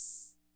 OHH2.wav